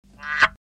chant 2